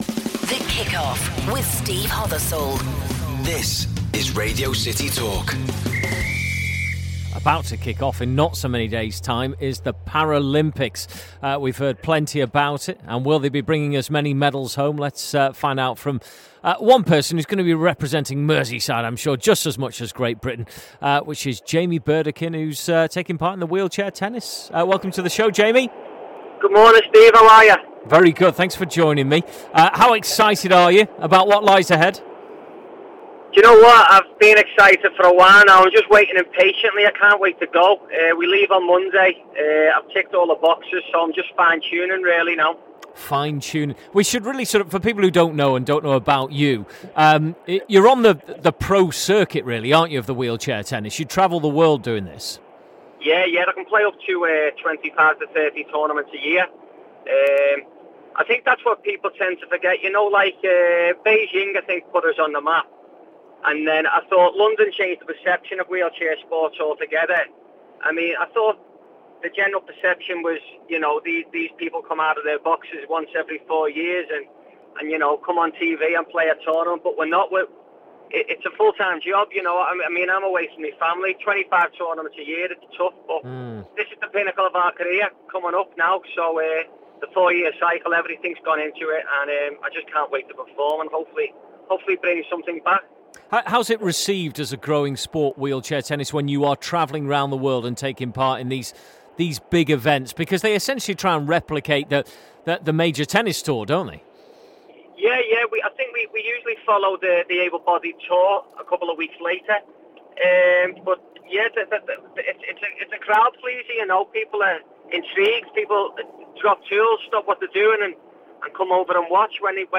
Wheelchair tennis player